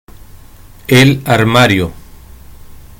Pronunciation Es El Armario (audio/mpeg)